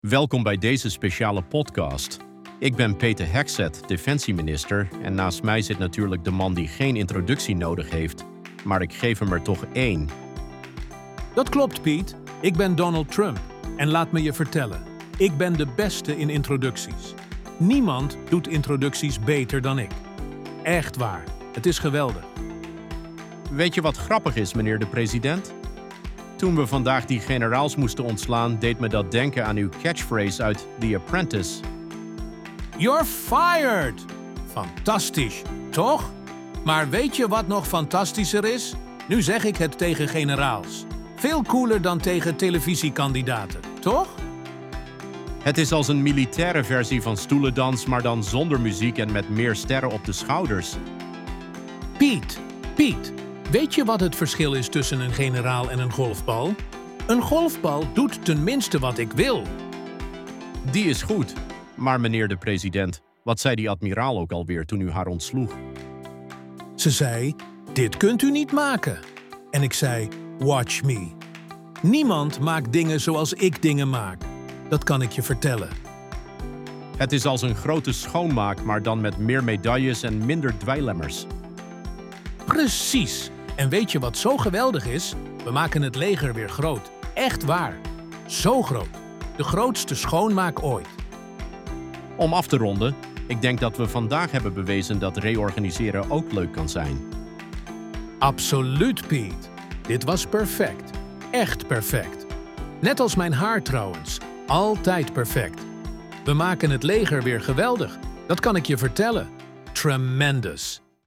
Podcast gegenereerd van tekst content (3264 karakters)